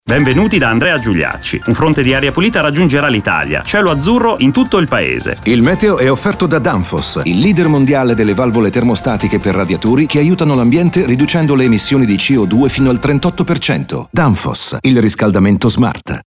La campagna è in onda in questi giorni su RTL e sarà trasmessa fino al 24 settembre.